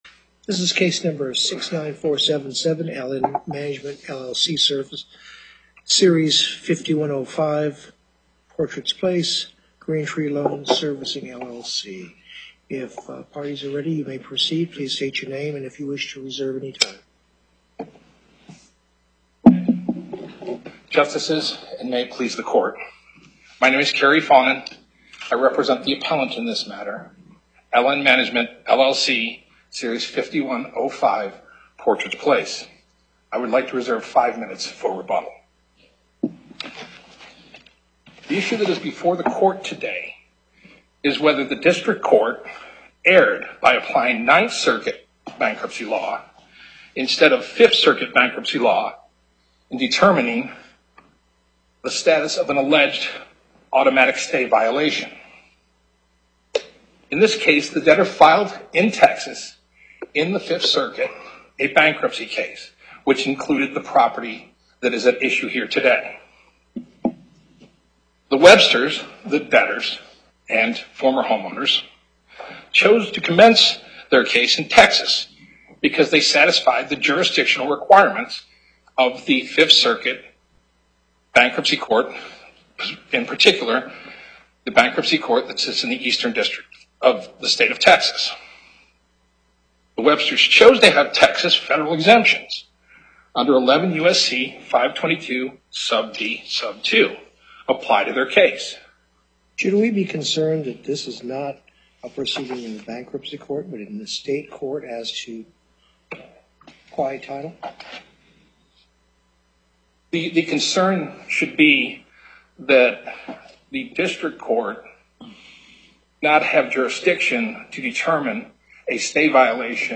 Loading the player Download Recording Docket Number(s): 69477 Date: 06/15/2017 Time: 11:30 A.M. Location: Las Vegas Before the Southern Nevada Panel, Justice Douglas Presiding.